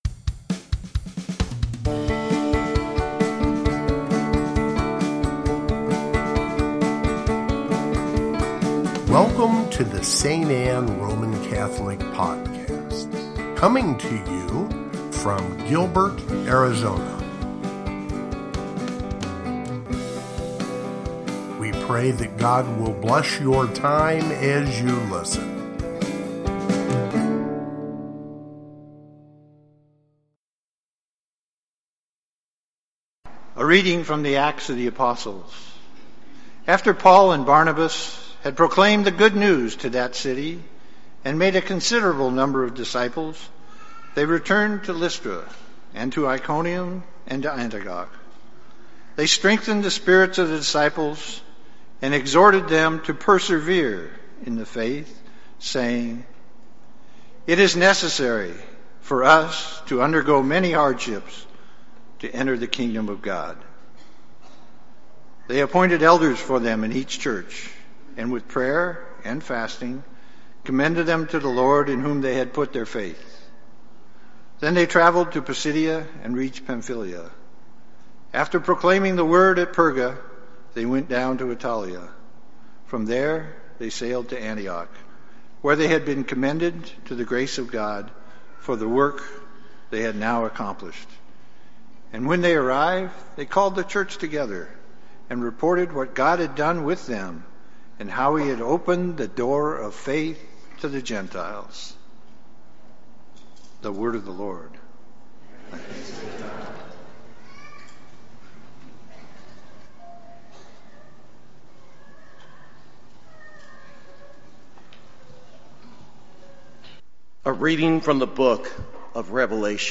Gospel, Readings, Easter